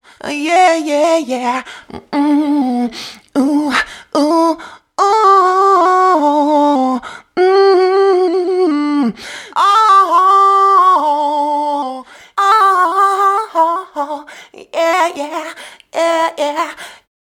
Using Gsnap in Audacity can produce a generic obvious autotune effect (Cher / T-Pain) for free …